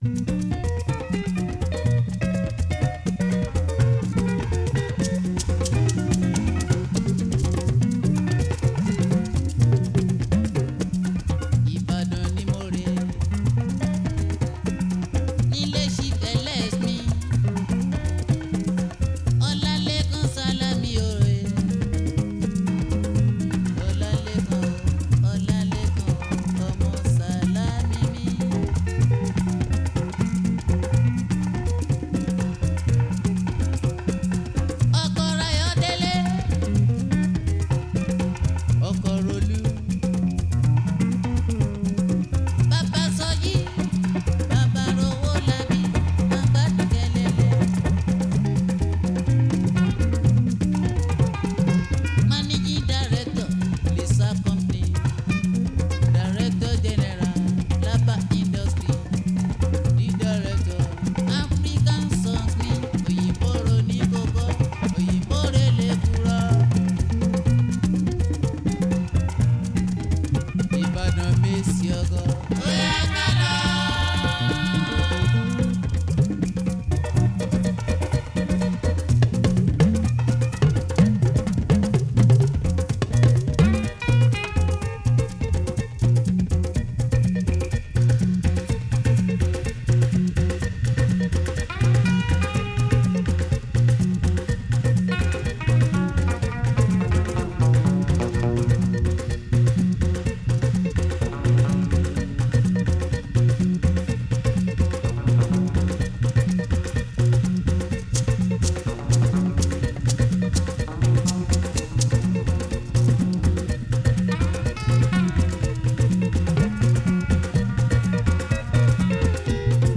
is a Nigerian jùjú singer